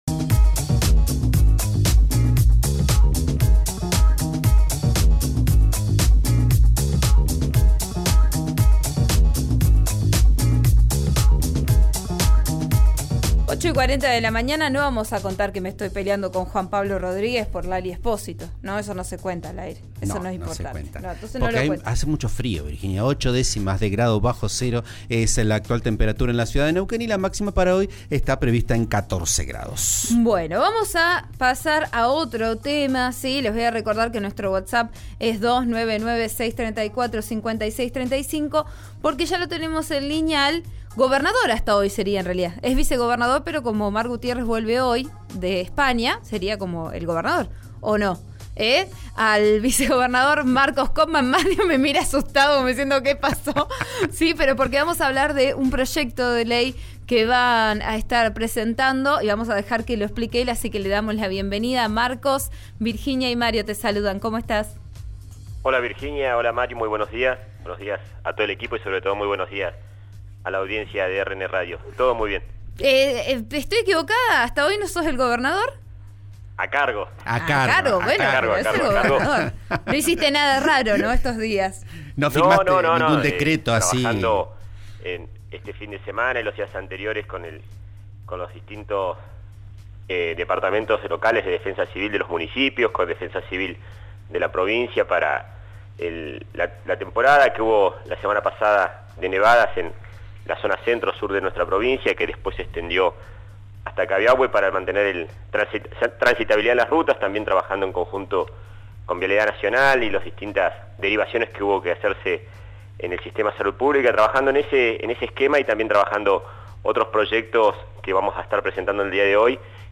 En diálogo con Vos A Diario, por RN Radio, Koopmann indicó que el proyecto busca favorecer cualquier tipo de industrialización o valor agregado que se le pueda aplicar a los hidrocarburos dentro de la provincia.